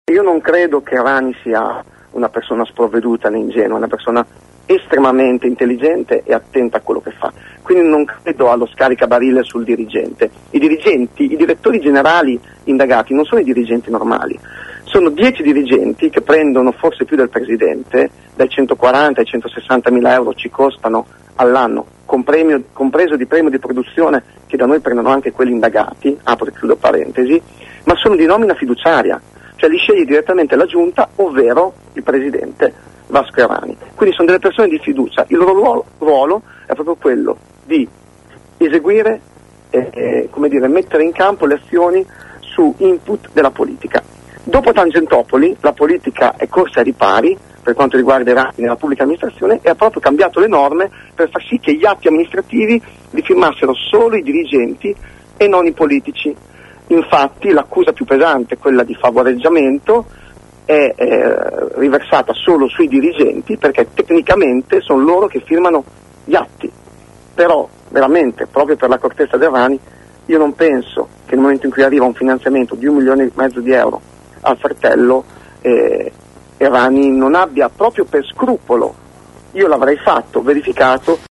Giovanni Favia, capogruppo in Regione del M5S, chiama il Pd a rispettare il proprio statuto nel caso in cui il governatore sia processato. “Nulla di personale contro Errani, lo condanno politicamente”, ha detto ad AngoloB Favia, convinto che l’aspetto più grave della vicenda non sia l’accusa di falso ideologico ma quello che definisce uno “scaricabarile” sui dirigenti coinvolti nell’inchiesta.